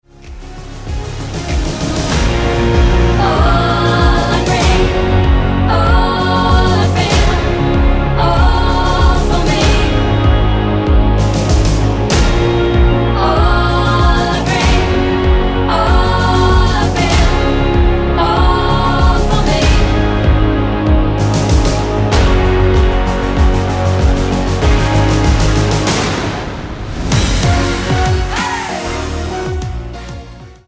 --> MP3 Demo abspielen...
Tonart:Db mit Chor